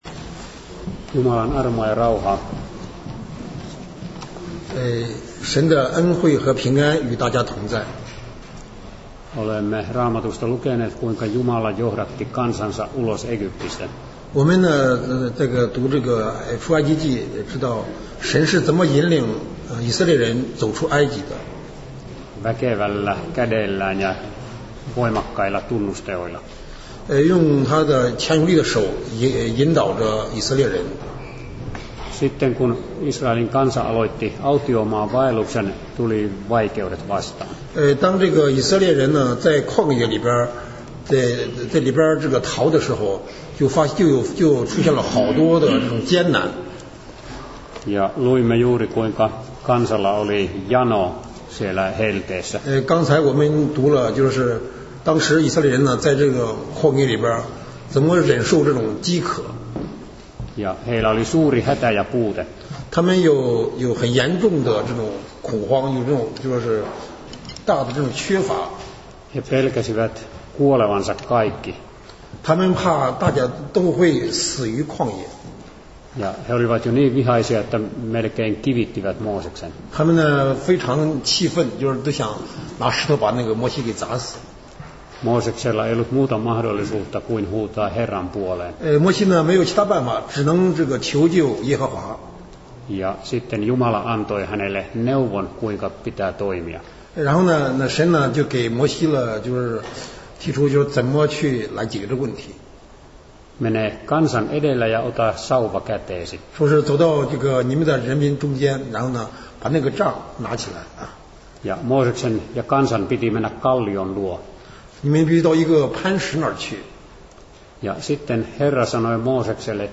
讲道
（芬译中） 地点：北欧华人基督教会 赫尔辛基福音堂